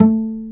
Pizz(2)_A3_22k.wav